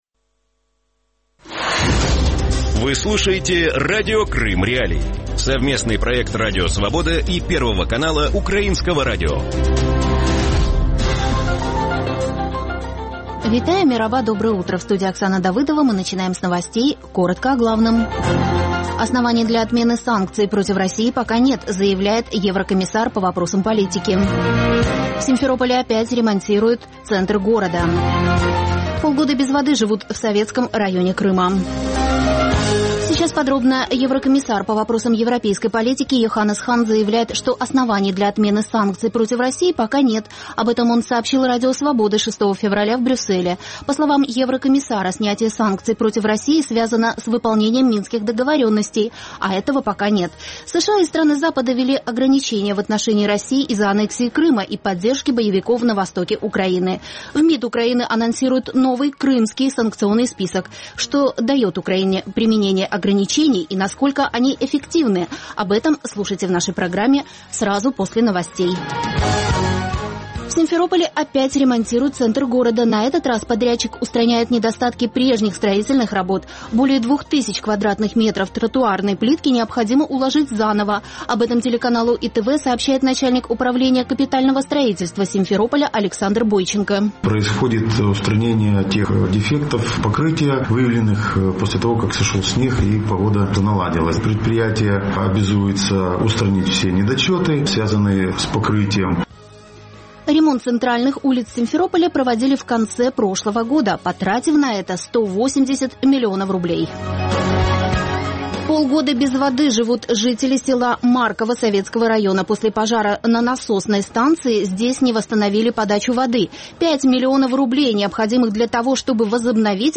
Утренний выпуск новостей о событиях в Крыму. Все самое важное, что случилось к этому часу на полуострове.